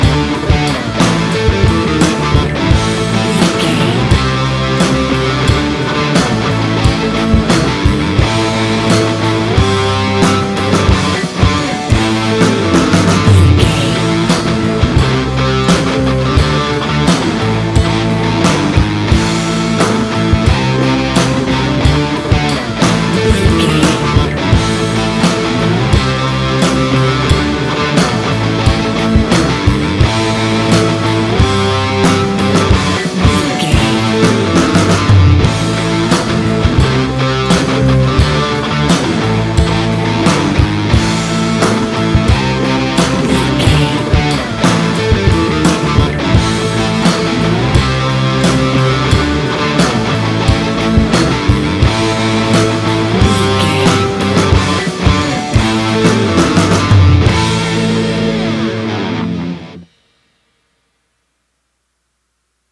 Ionian/Major
A♭
hard rock
heavy rock
distortion
instrumentals